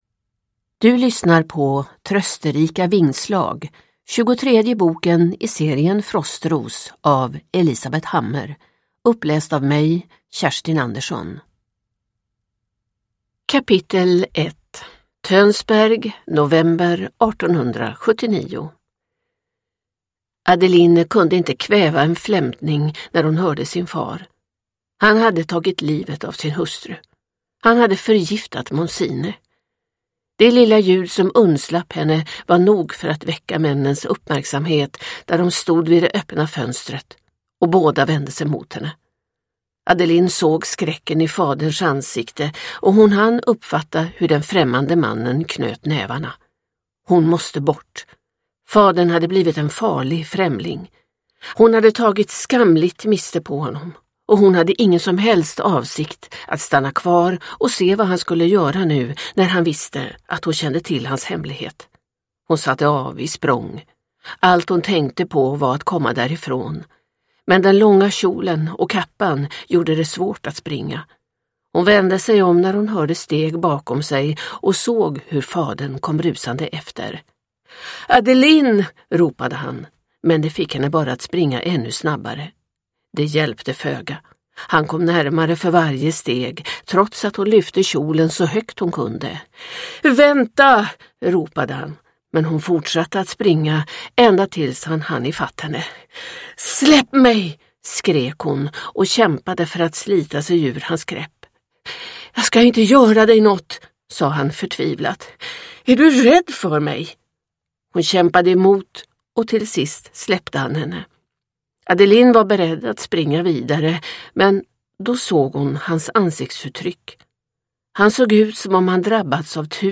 Trösterika vingslag – Ljudbok